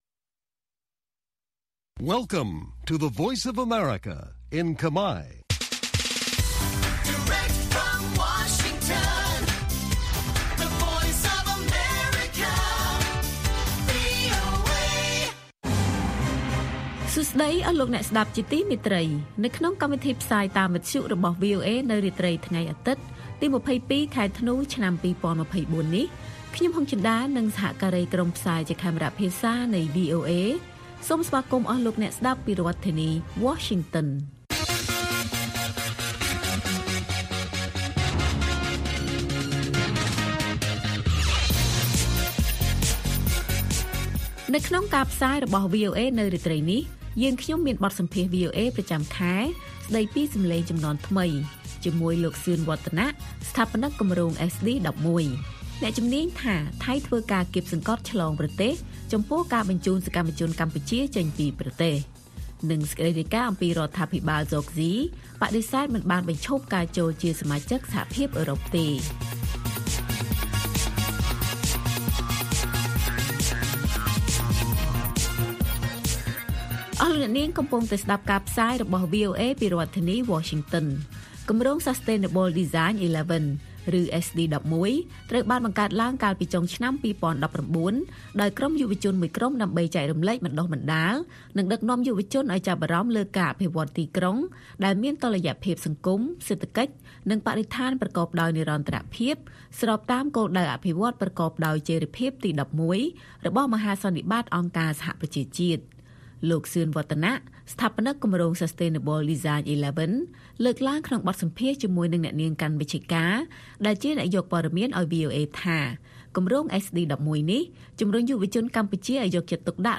ព័ត៌មានពេលរាត្រី ២២ ធ្នូ៖ បទសម្ភាសន៍